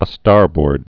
(ə-stärbərd)